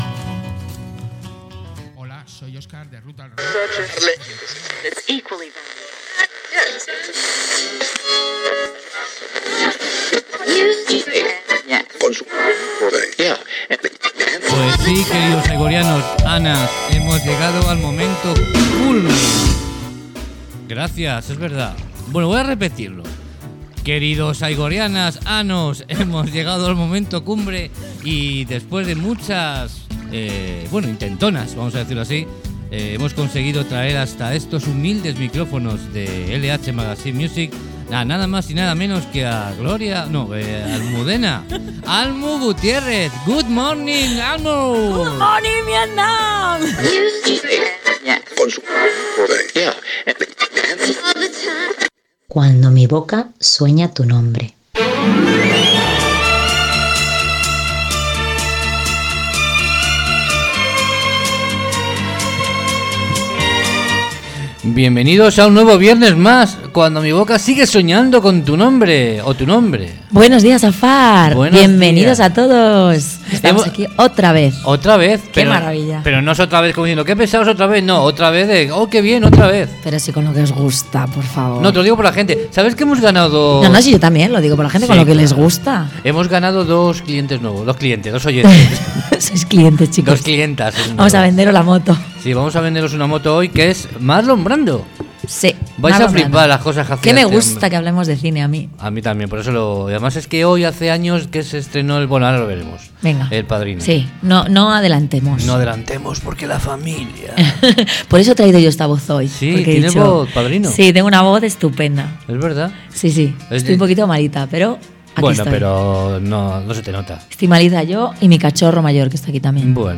Spirit in the Night “Entrevista